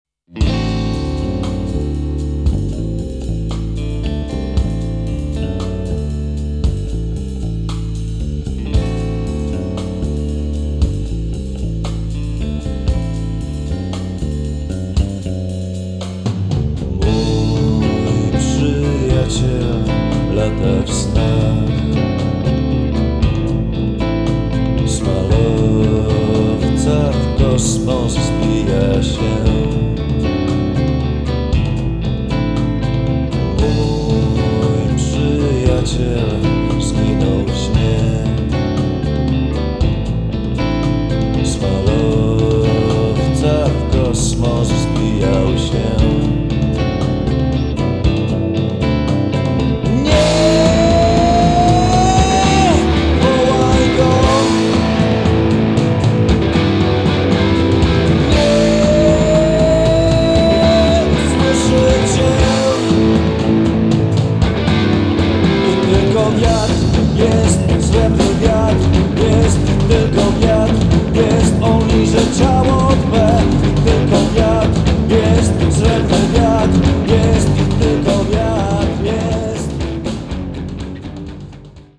żywiołowość rock'n'rolla, punkową
rozwiązania muzyki awangardowej."